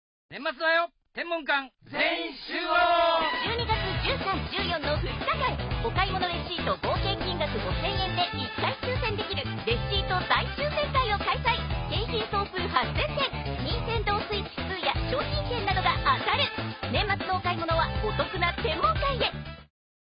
CM